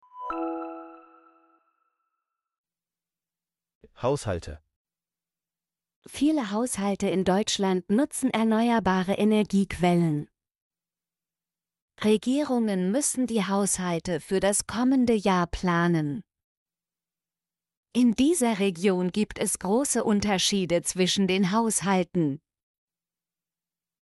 haushalte - Example Sentences & Pronunciation, German Frequency List